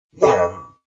Skel_COG_VO_grunt.mp3